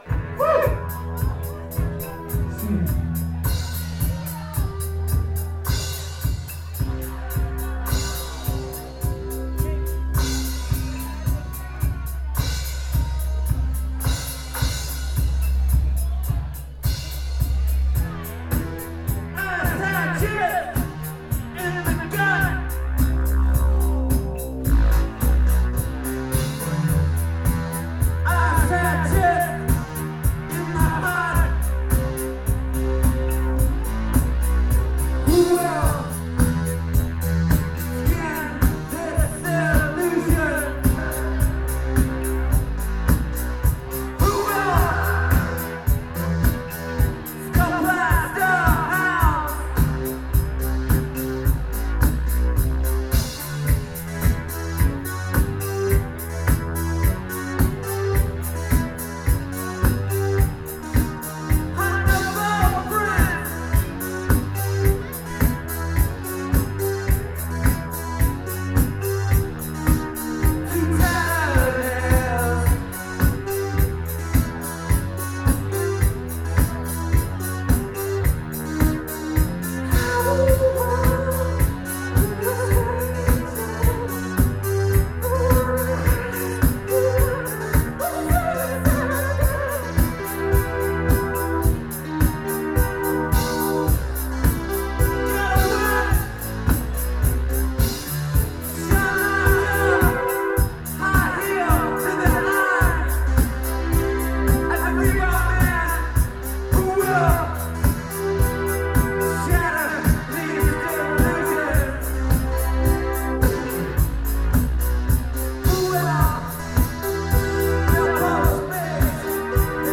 2006-10-26 Chop Suey – Seattle, WA